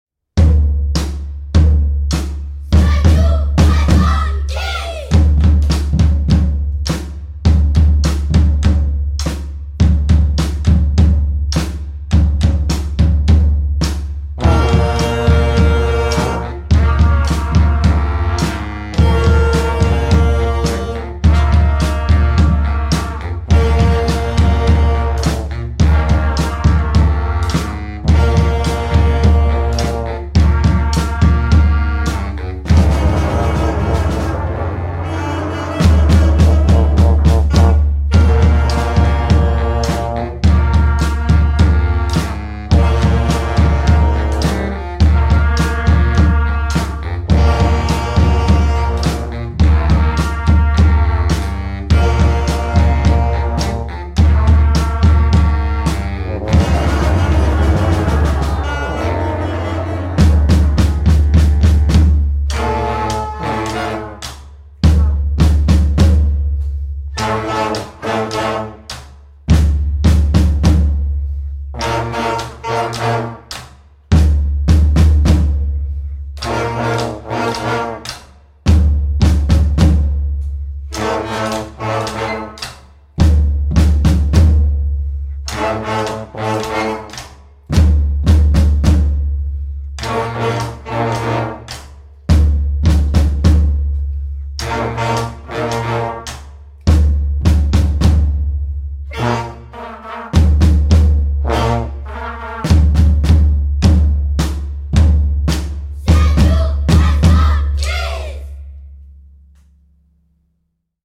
Apparu au XIXe siècle à la Nouvelle-Orléans, le Brass band est un ensemble musical composé d’instruments de la famille des cuivres et d’une section plus ou moins importante de percussions. Le répertoire, résolument jazz et festif, est interprété par une vingtaine de musiciens, sur scène ou directement dans la rue.
Morceaux enregistrés au studio Transversal de Vannes à l'occasion du stage d'été de l’édition 2024.
The New Breizh Band Kids